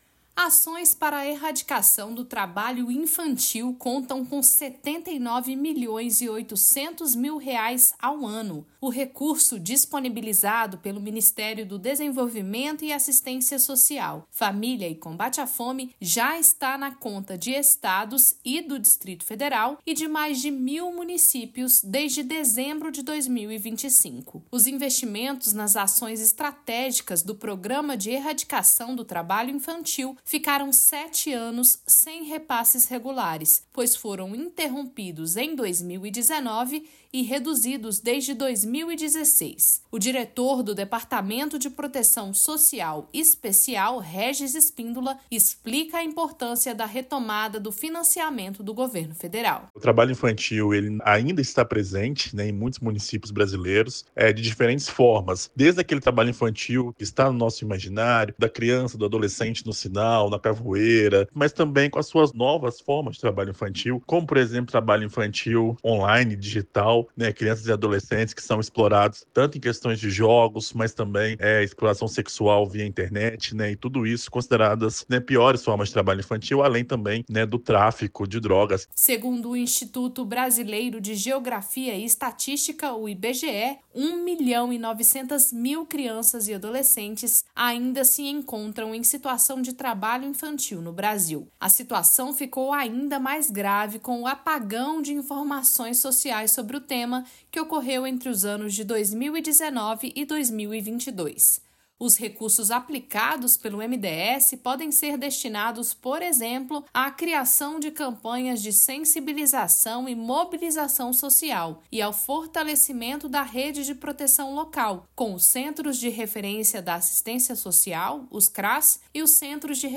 Boletim do MDS